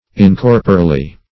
incorporally - definition of incorporally - synonyms, pronunciation, spelling from Free Dictionary Search Result for " incorporally" : The Collaborative International Dictionary of English v.0.48: Incorporally \In*cor"po*ral*ly\, adv.